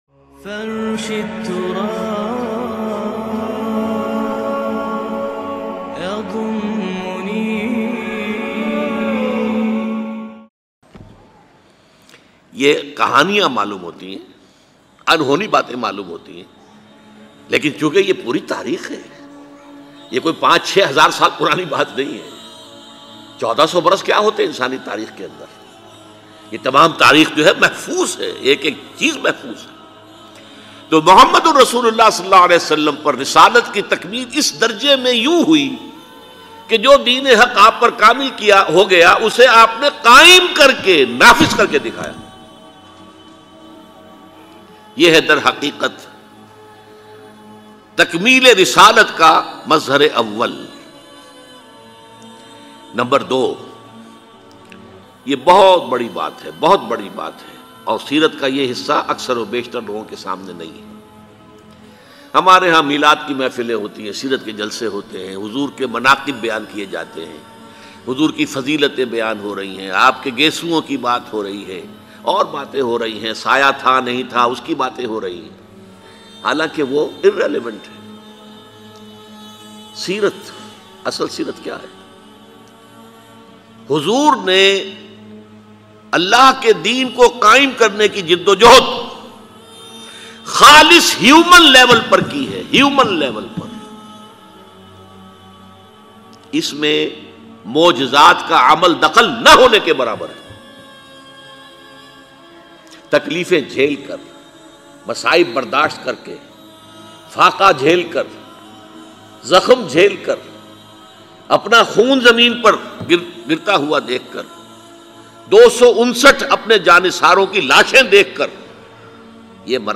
Huzoor Ki Ansoo Bhari DUA Dr Israr Ahmed EmotionaL Bayan MP3 Download
Dr Israr Ahmed R.A a renowned Islamic scholar.